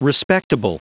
Prononciation du mot respectable en anglais (fichier audio)
Prononciation du mot : respectable